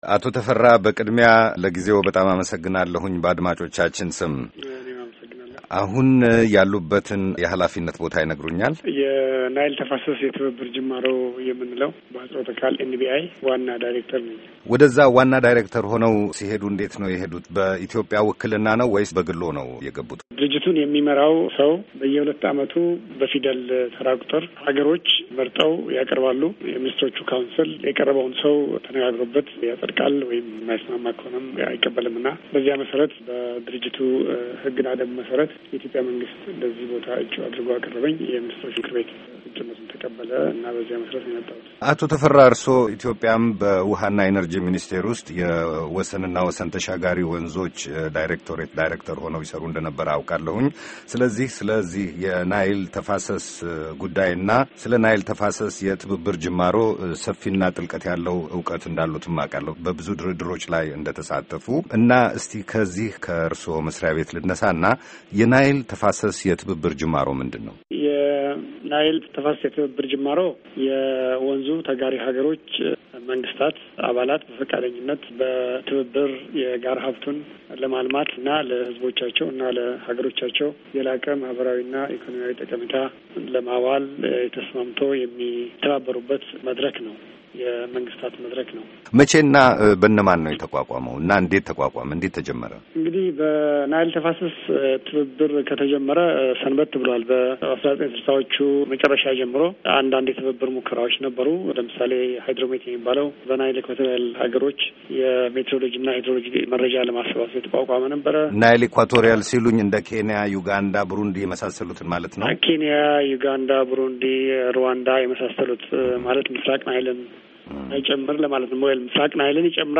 Interview with VOA